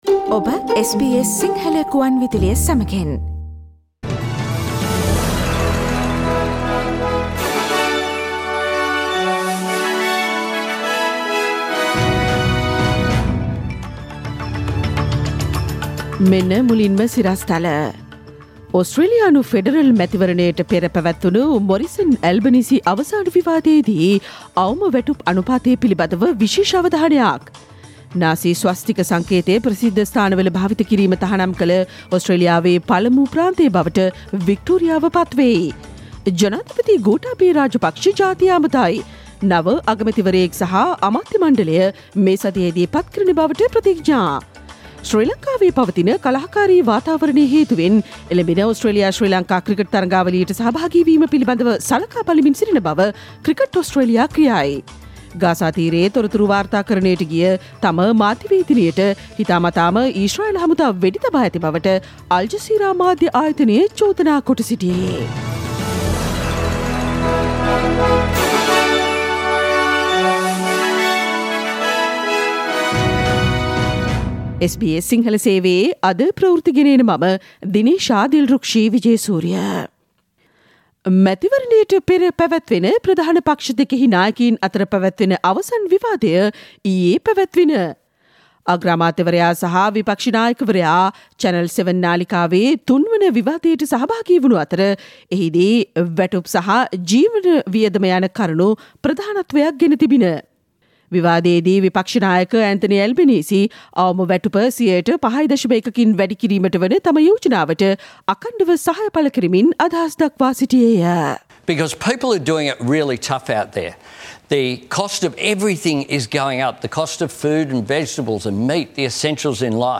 ඉහත චායාරූපය මත ඇති speaker සලකුණ මත click කොට මැයි 12 වන බ්‍රහස්පතින්දා SBS සිංහල ගුවන්විදුලි වැඩසටහනේ ප්‍රවෘත්ති ප්‍රකාශයට ඔබට සවන්දිය හැකියි.